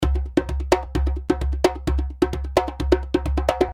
Djembe loops - 130 bpm (27 variations)
Real djembe loops playing at 130 bpm.
The Djembe loops are already in mix mode, with light compression and EQ.
The Djembe was recorded using vintage neumann u87 as main microphone, And 2 451 AKG microphones for the stereo ambient sound. The loops are dry with no effect , giving you freedom, adding the right effect to your project. Djembe is west african drum but the loops here are more ethnic, arabic and brazilian style .